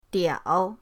diao3.mp3